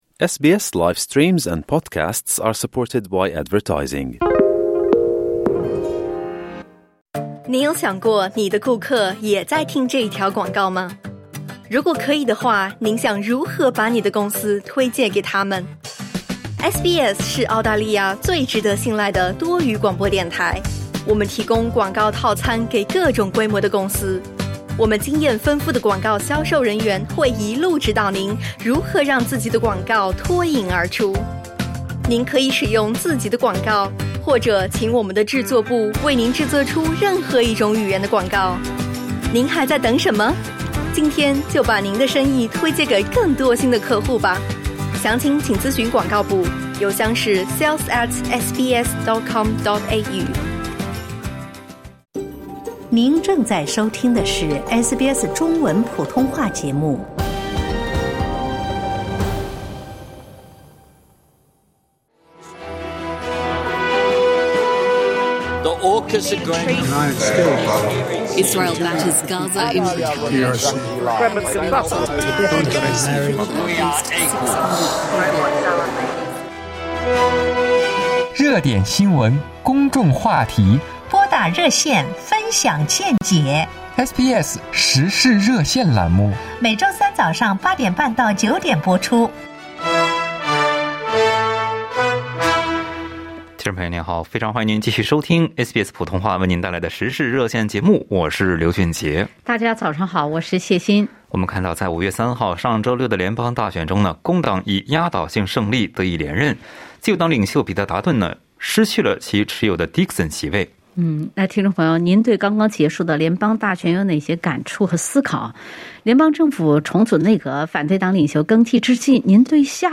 在SBS普通话《时事热线》节目中，听友们就此分享了各自的看法。